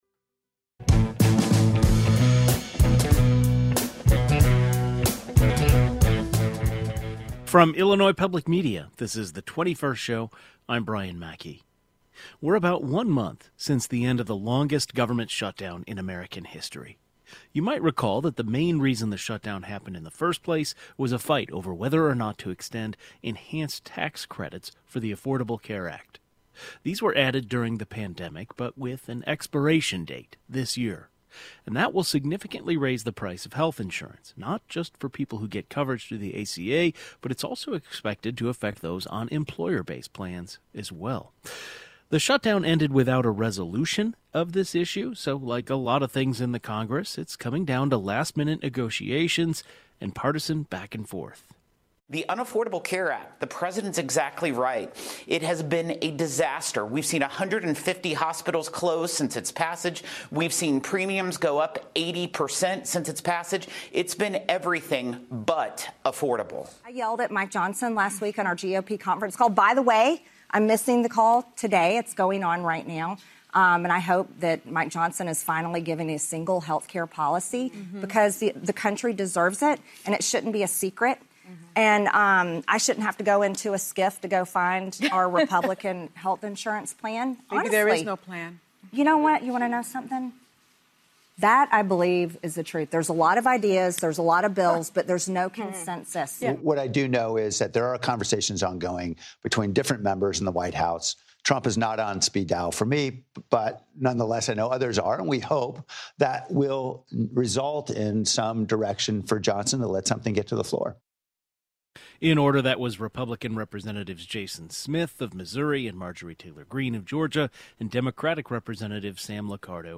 The 21st Show is Illinois' statewide weekday public radio talk show, connecting Illinois and bringing you the news, culture, and stories that matter to the 21st state.
A health care policy expert and a journalist who covers health care join the conversation.